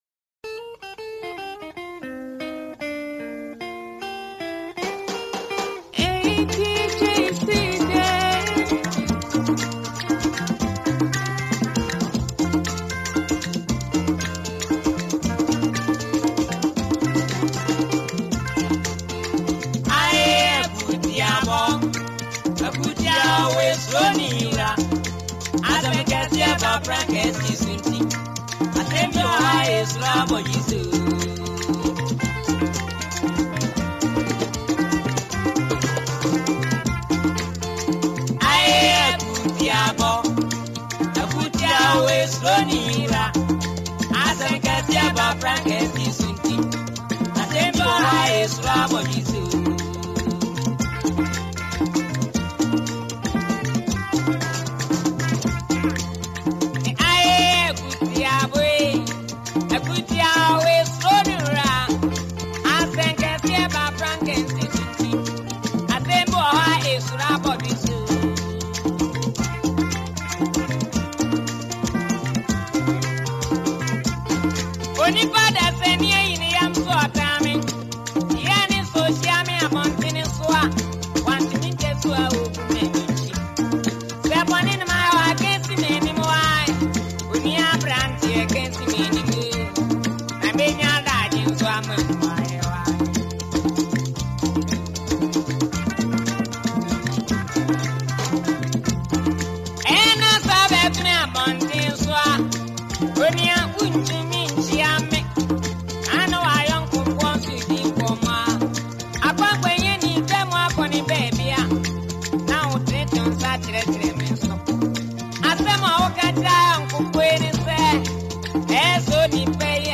a trending Ghanaian disc jockey
highlife mix